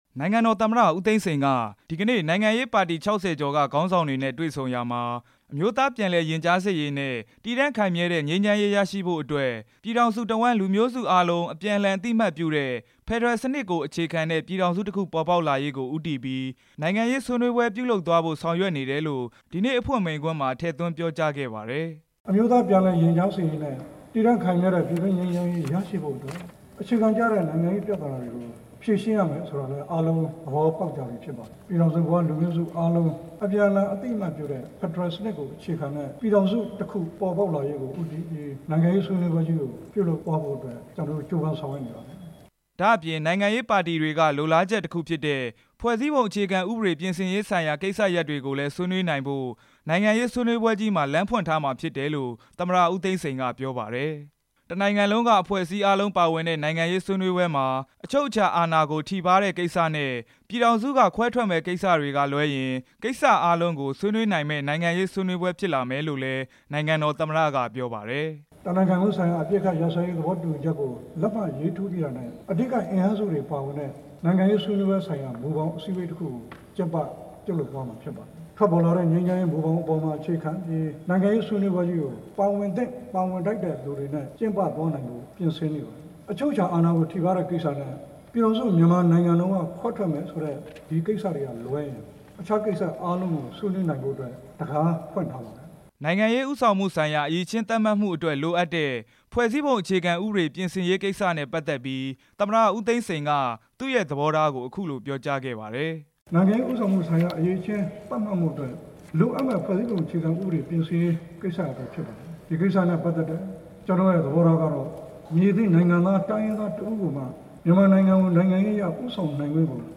တွေ့ဆုံပွဲအကြောင်း တင်ပြချက်